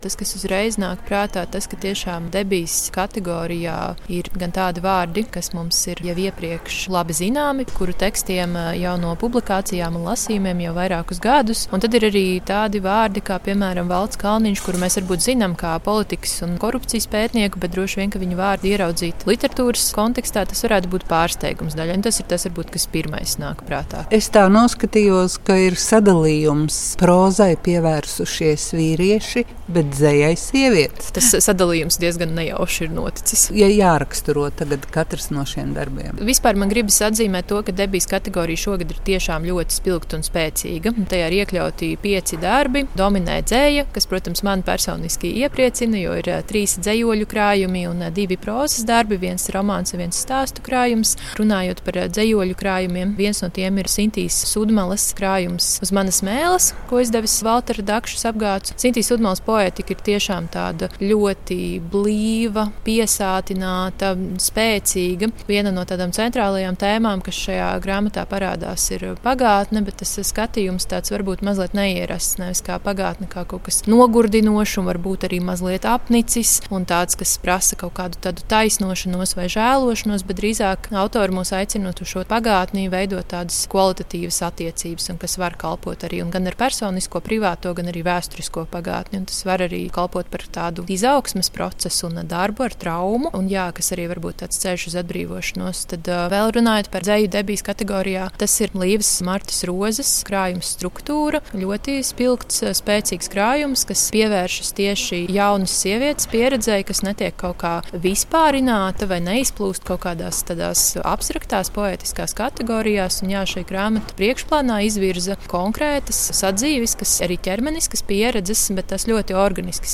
Saruna ar autoru – Grāmatu stāsti – Lyssna här